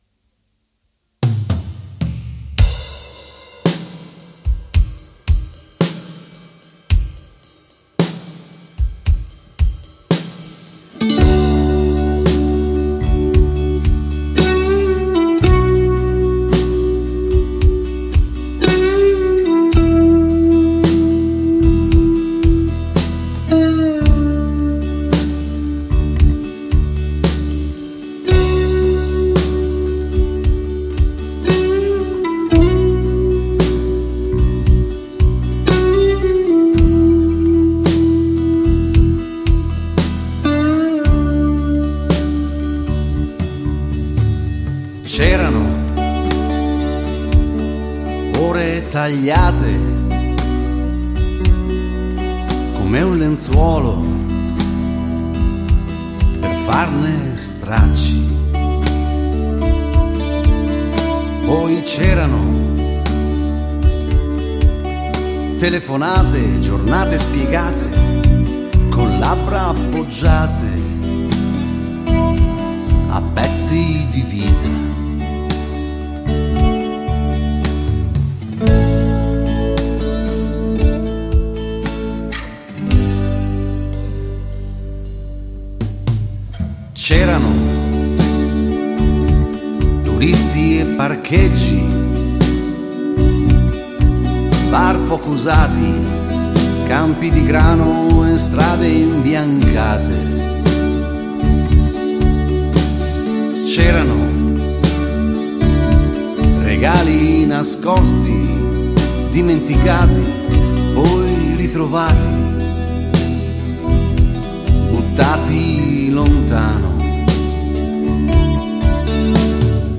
Luogo esecuzioneDuna Studio - Ravenna
GenerePop / Musica Leggera